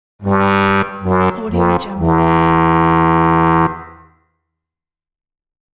Fail Trombone Sound Effect: Unblocked Meme Soundboard